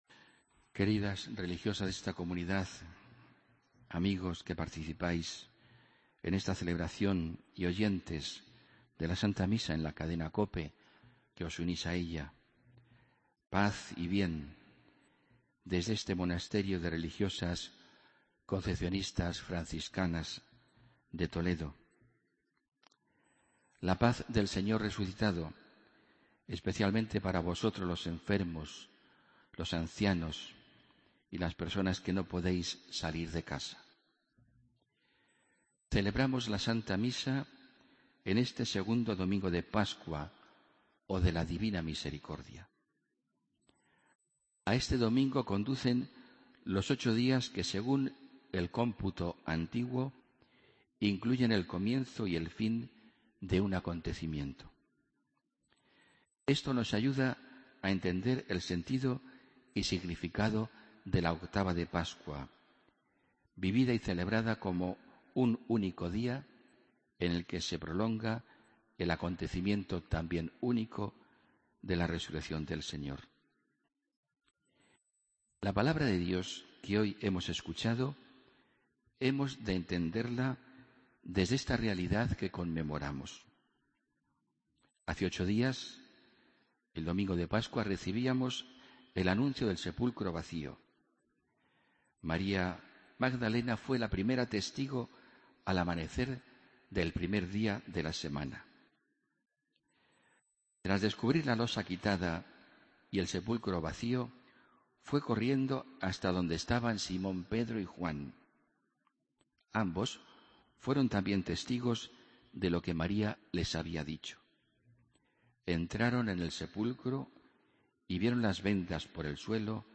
Homilía del domingo 12 de abril de 2015